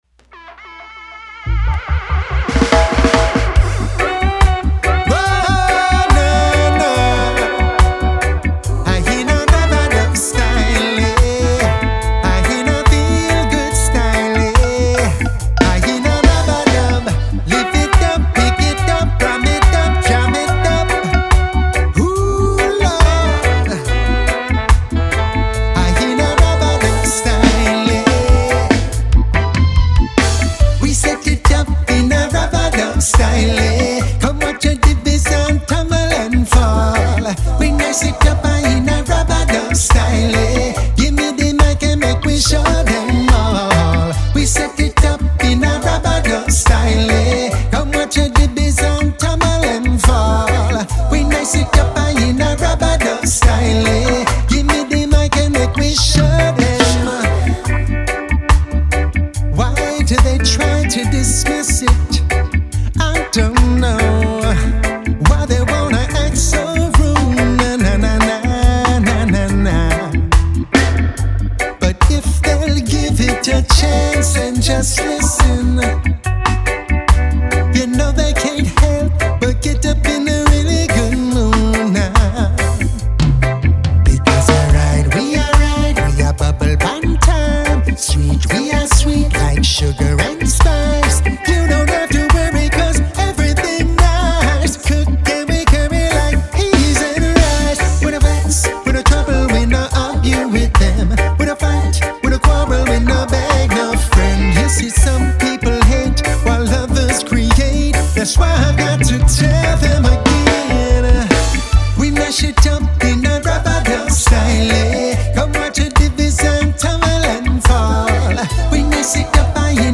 Reggae | Roots | Dub | Conscious Vibes — Reimagined